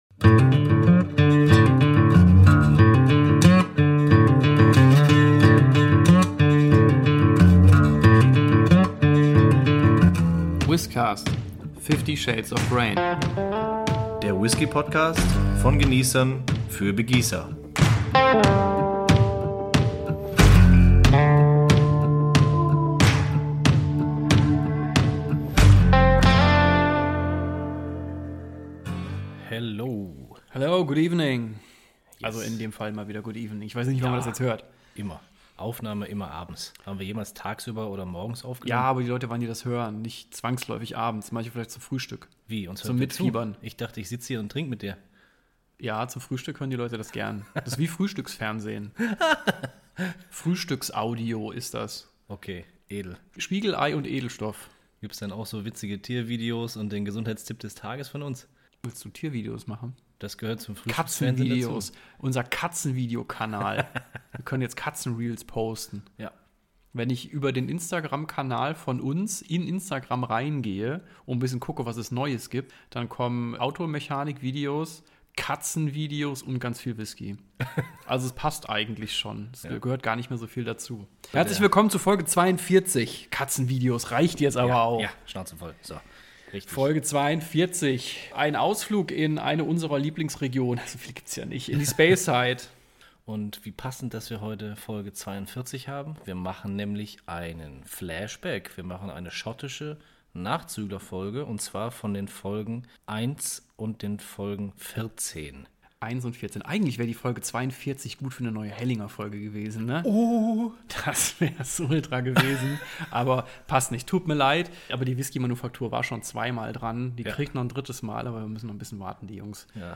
Von randalierender Katze im Hintergrund bis hin zu verschlissenen Flaschenverschlüssen ist mal wieder alles dabei! Zudem haben wir uns sogar an historisches Destillat und an unabhängige Abfüller aus der Schweiz gewagt!